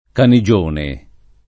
[ kani J1 ne ]